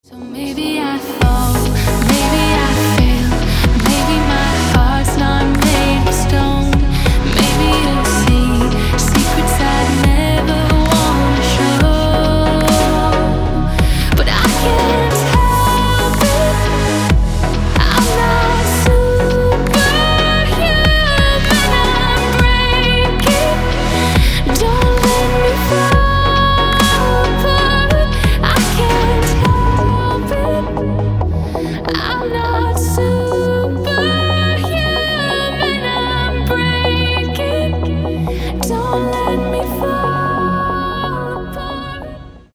Душевный рингтон с прекрасным женским вокалом.